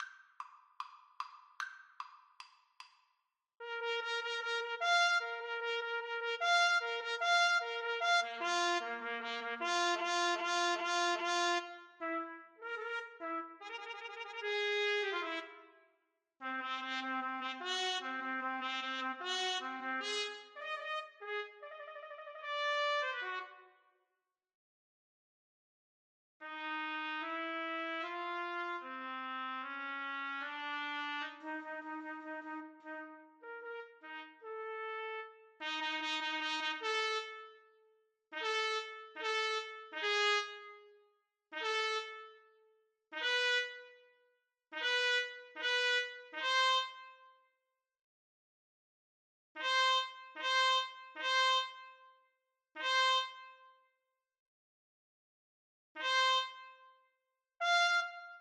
= 150 Allegro Moderato (View more music marked Allegro)
4/4 (View more 4/4 Music)
Classical (View more Classical Trumpet Duet Music)